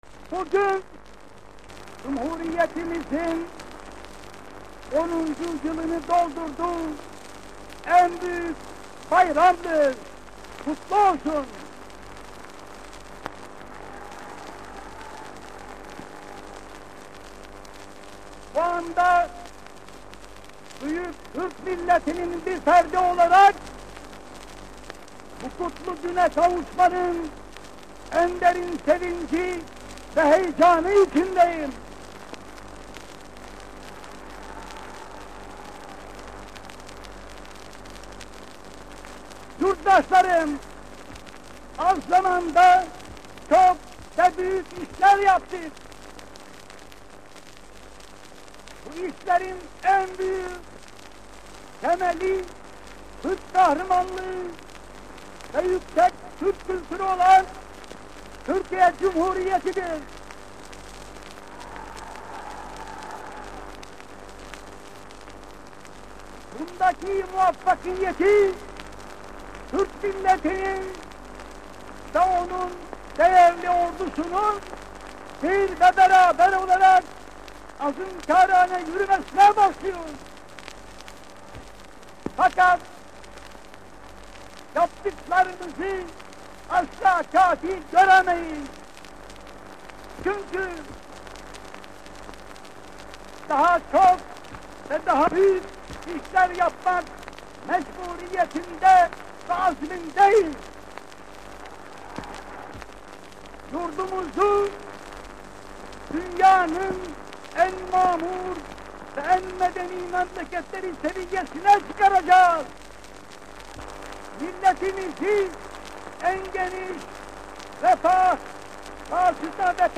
ATA´NIN KENDİ SESİNDEN DİNLEMEK İÇİN